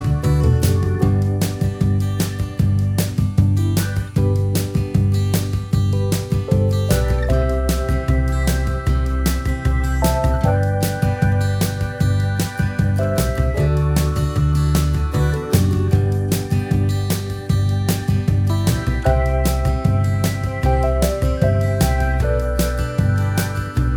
Minus All Guitars Except Acoustics Soft Rock 3:12 Buy £1.50